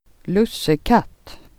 lusse|katt [²l'us:ekat:]